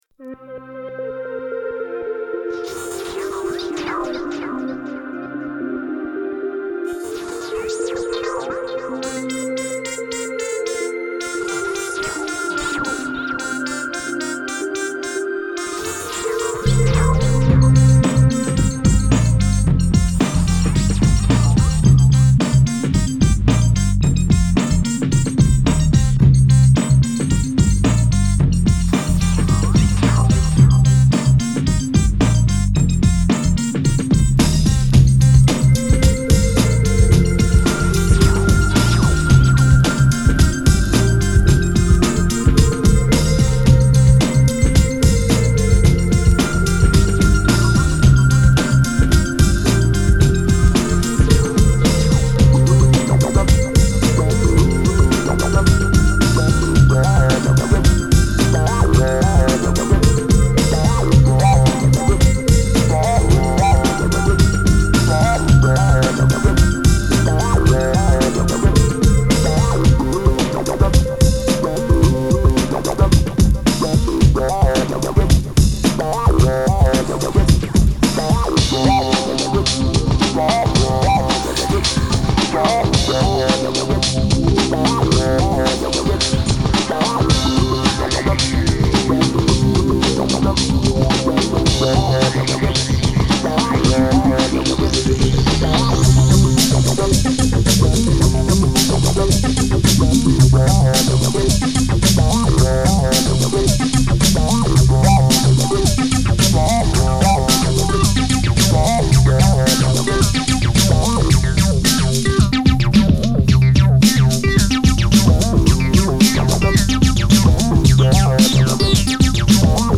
Breakbeat 8Mb